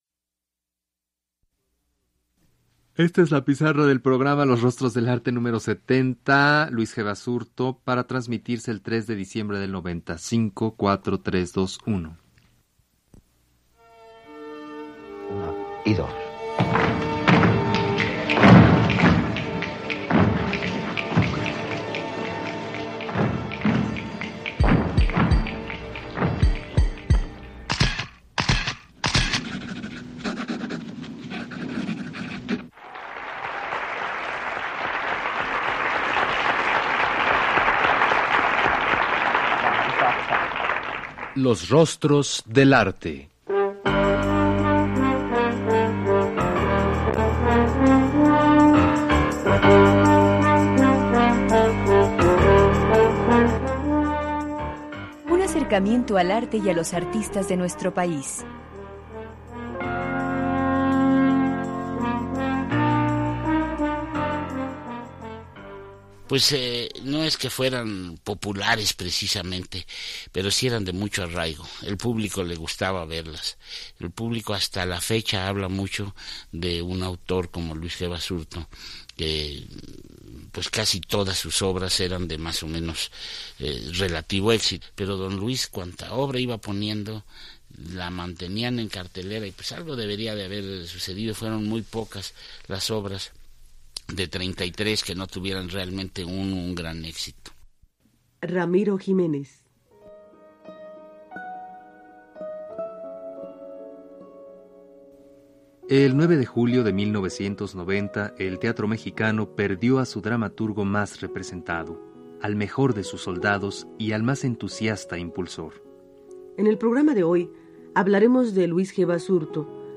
Escucha el programa narrado y dramatizado “Los rostros del arte”, transmitido el 3 de diciembre de 1990